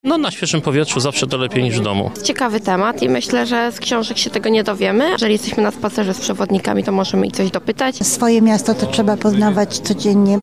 mowia_uczestnicy_spaceru.mp3